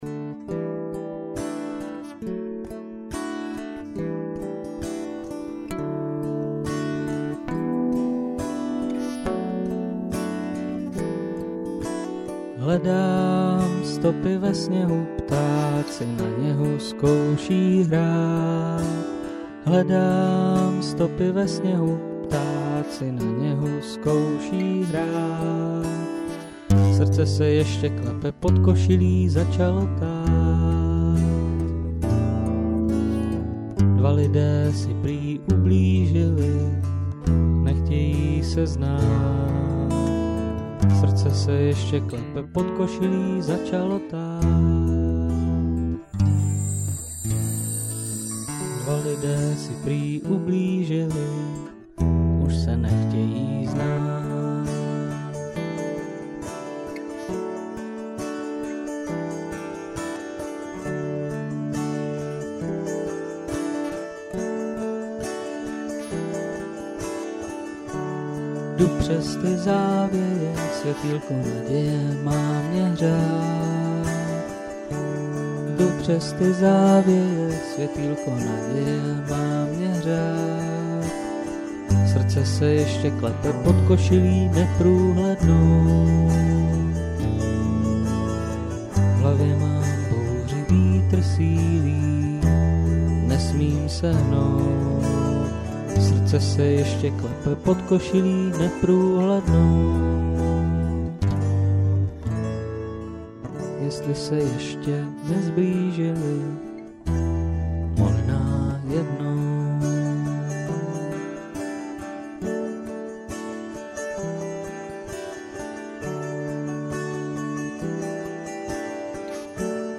kategorie ostatní/písně